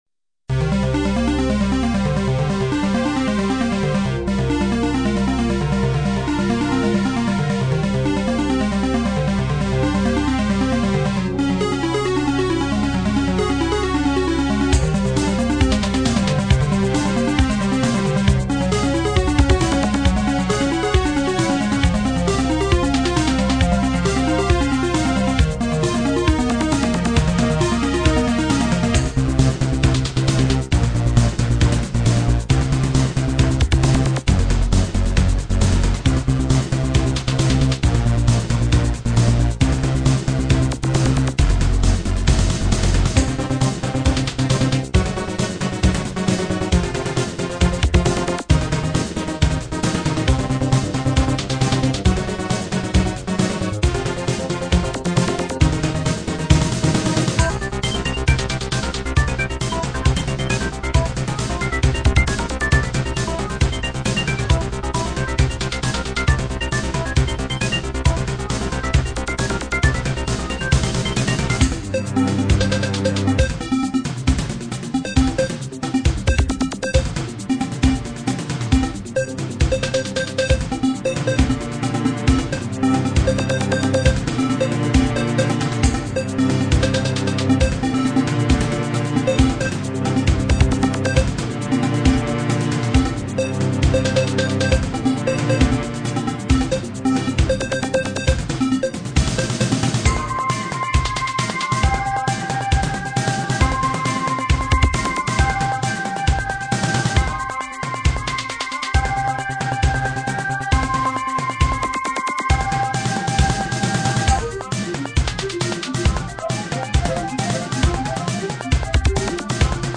recorded midi